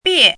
chinese-voice - 汉字语音库
bie4.mp3